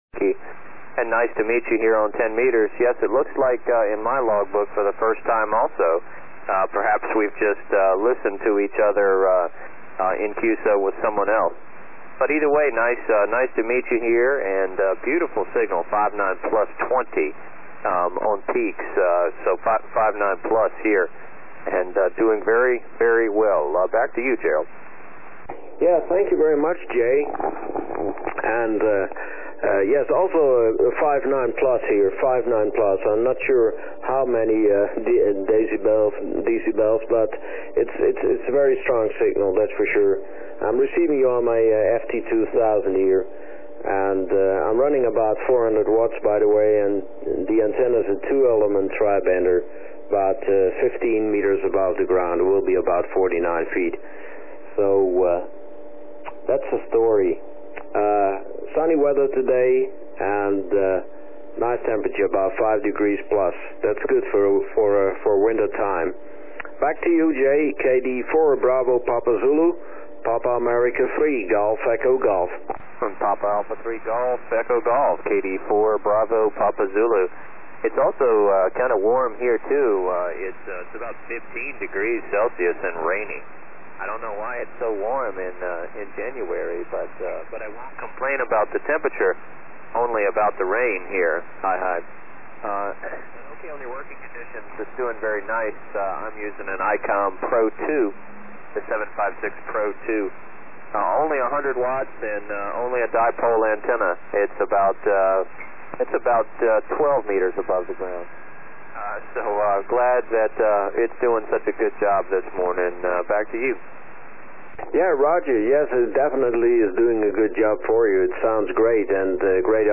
This op in the Netherlands took the time to send along a short audio clip of our QSO.  It is always nice to hear what you sounds like on the other side of the planet.